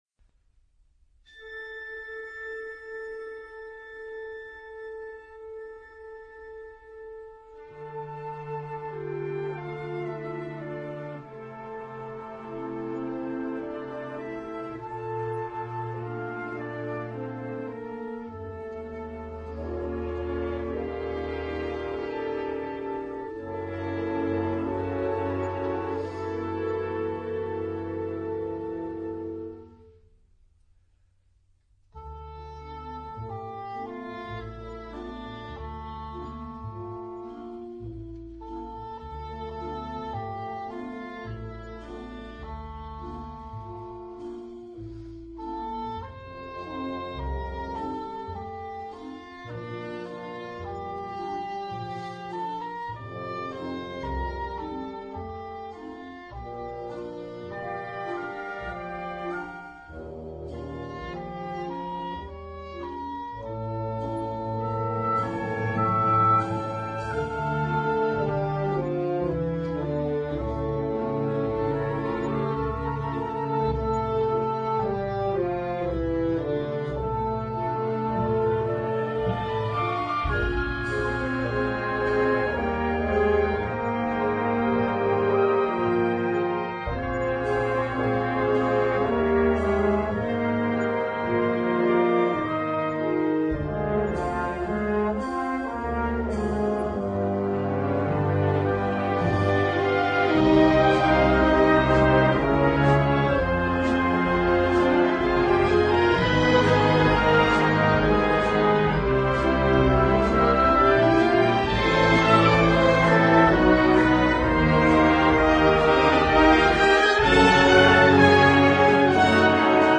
Concours National 2018
SymphonieDesSaisons-Orch.mp3